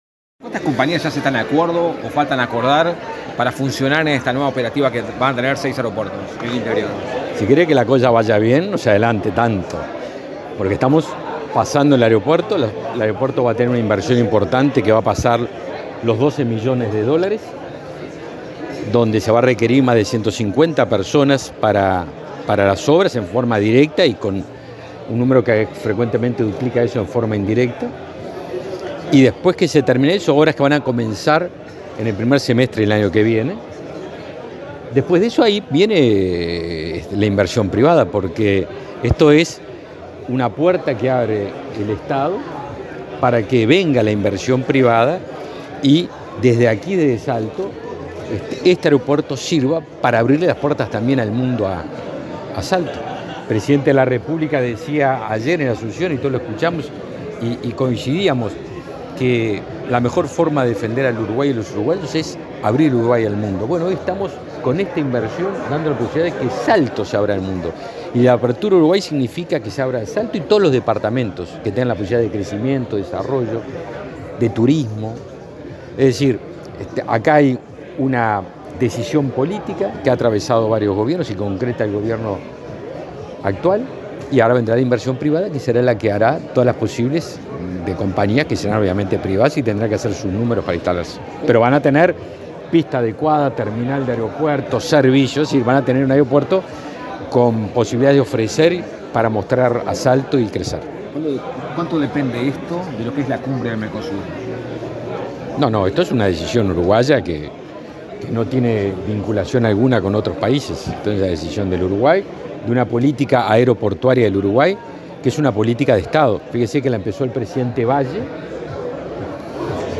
Declaraciones a la prensa del ministro de Defensa, Javier García
Este viernes 22 en Salto, el ministro de Defensa Nacional, Javier García, participó en el acto de traspaso de la gestión del aeropuerto internacional